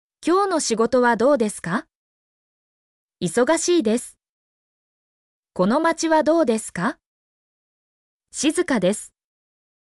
mp3-output-ttsfreedotcom-15_KQkUIroe.mp3